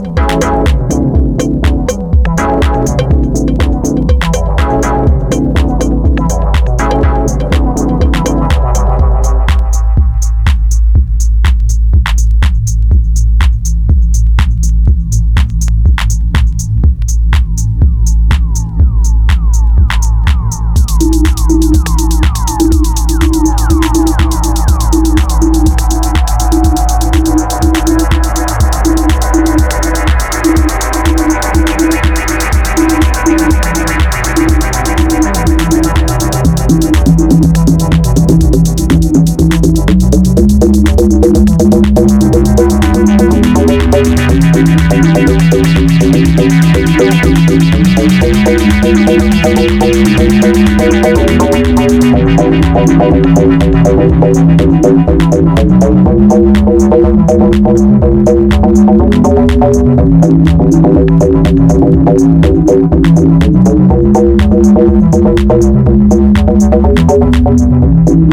新世代シンセ・ブギー/エレクトロ・ファンク名曲をカップリング！